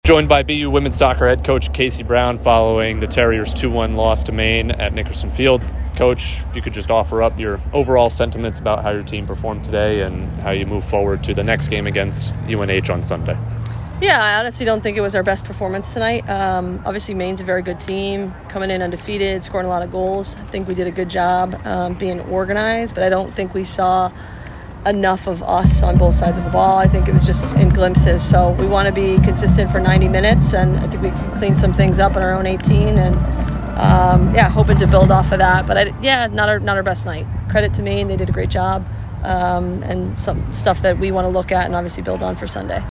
WSOC_Maine_Postgame.mp3